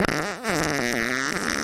• Качество: высокое
Звук Мамы